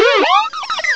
cry_not_drifloon.aif